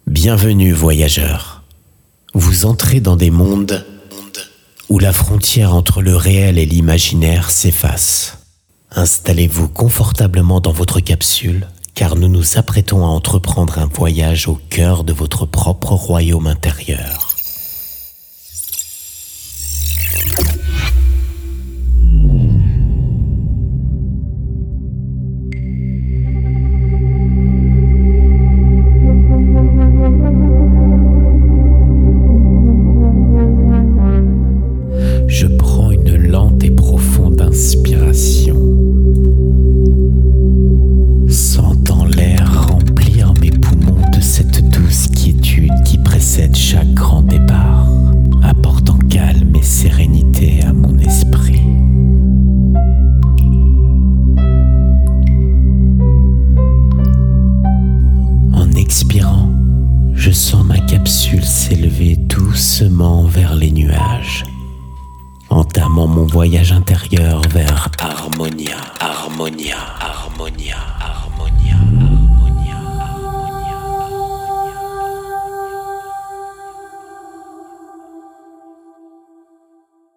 Plongez dans un conte sonore méditatif en 3D. Partez à la rencontre du cristal de guérison au cœur d’un univers mystique et apaisant.